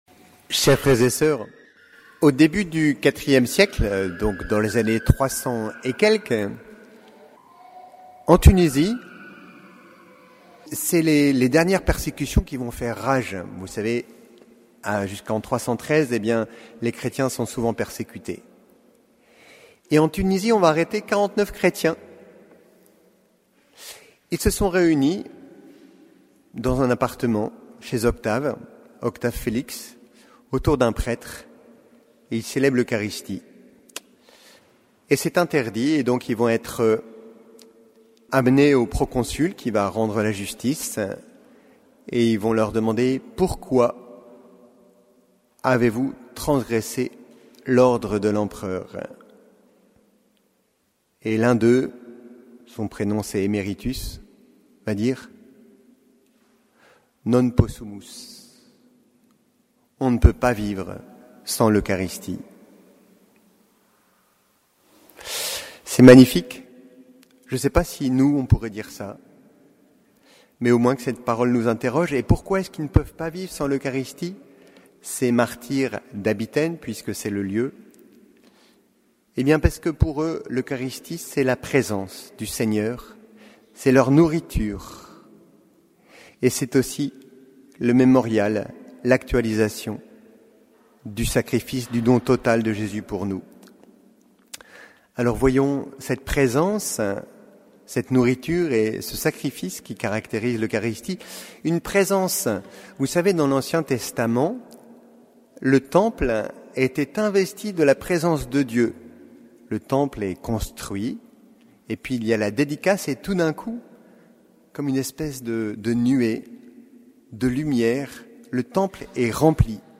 Homélie de la solennité du Saint Sacrement du Corps et du Sang du Christ